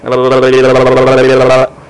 Dazzled Sound Effect
dazzled-1.mp3